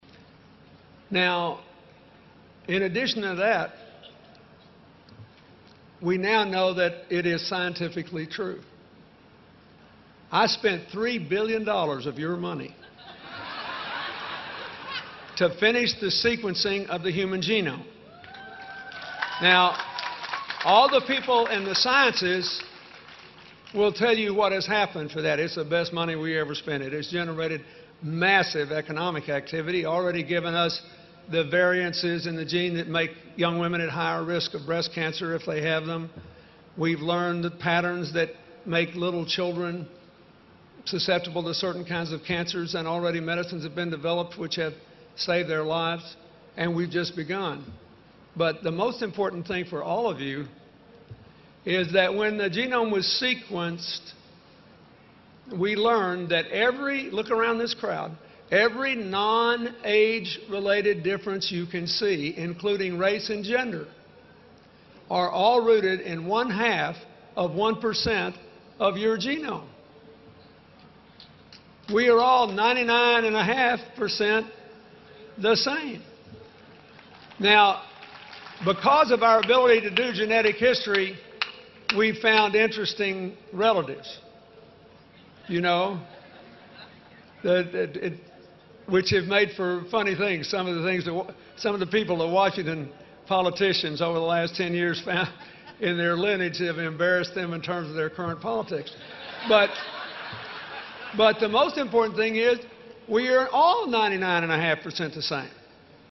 公众人物毕业演讲第130期:2013年克林顿在霍华德大学(9) 听力文件下载—在线英语听力室